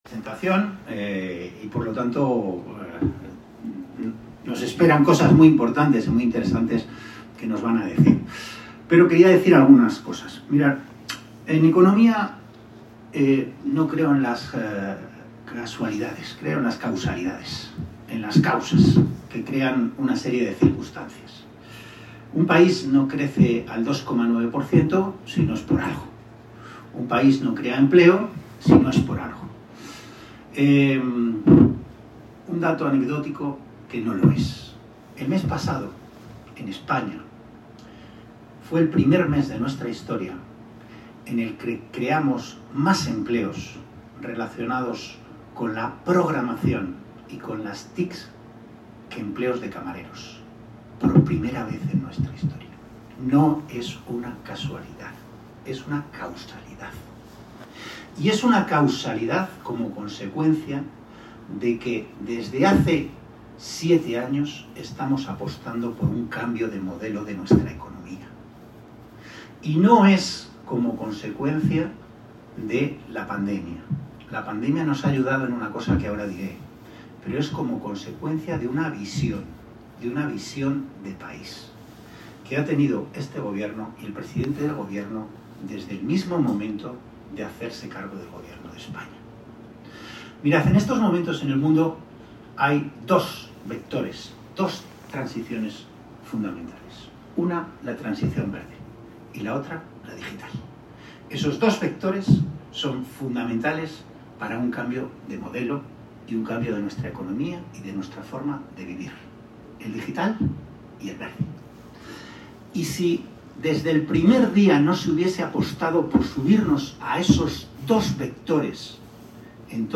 La jornada fue inaugurada por el secretario de Estado de Telecomunicaciones e Infraestructuras Digitales, Antonio Hernando, el secretario general del PSOE de Segovia y diputado en el Congreso, José Luis Aceves, y el alcalde de Cuéllar, Carlos Fraile, quienes coincidieron en subrayar que la industrialización, acompañada de la digitalización, debe convertirse en una palanca imprescindible para generar empleo, fijar población y garantizar un futuro de esperanza para las comarcas segovianas.